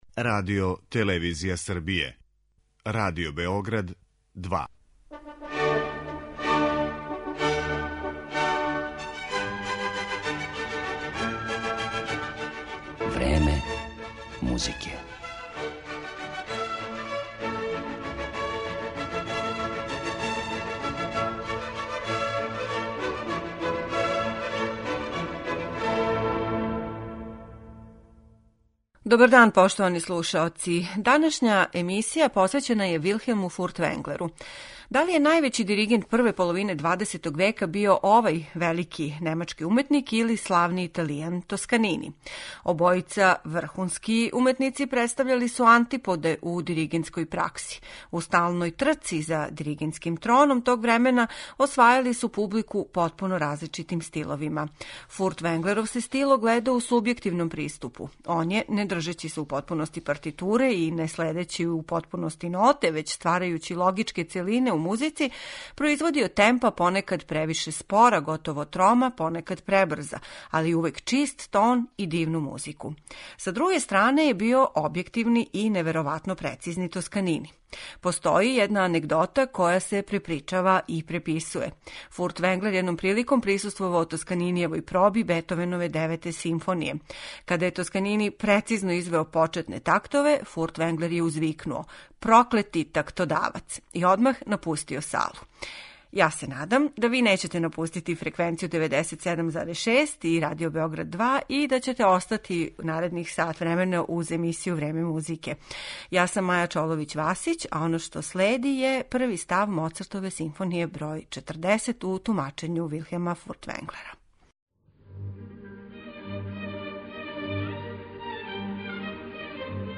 Једног од најоригиналнијих уметника XX века представићемо фрагментима из композиција Моцарта, Бетовена, Брамса, Вагнера и других.